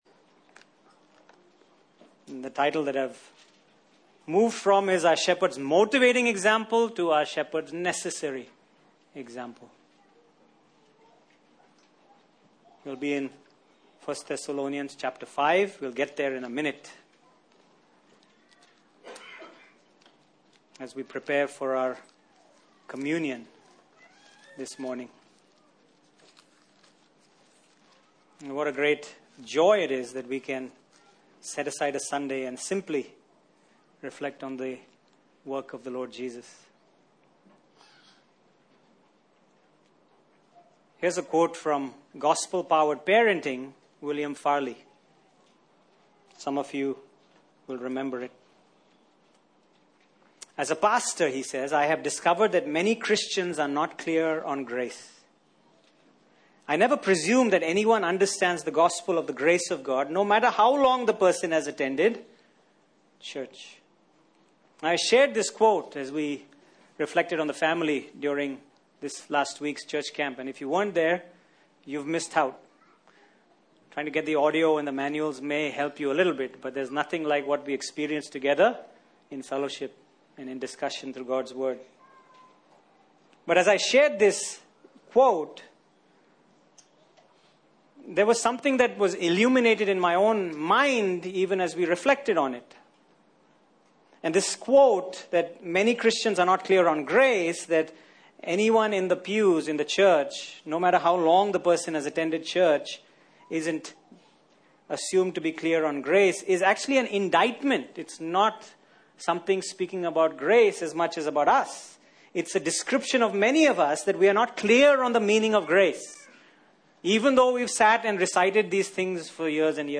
Passage: 1 Thessalonians 5:14 Service Type: Sunday Morning